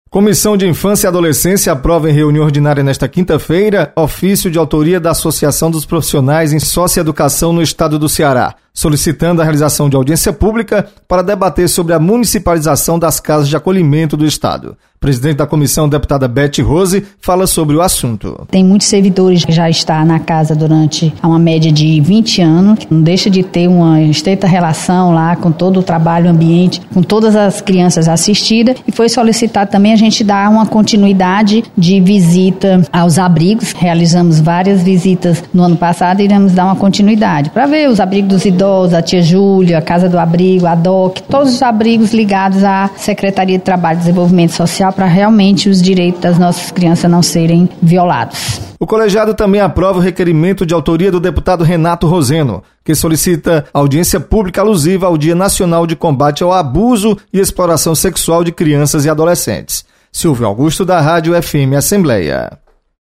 Comissão aprova realização de audiência para discutir a municipalização das casas de acolhimento. Repórter